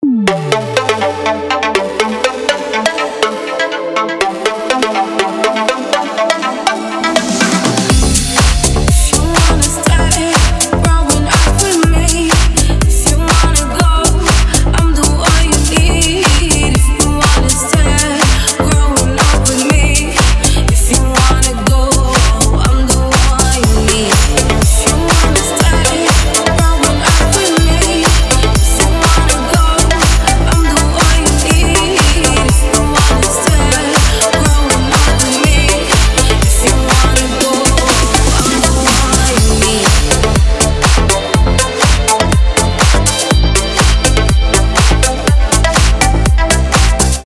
громкие
deep house
dance
чувственные
nu disco
Indie Dance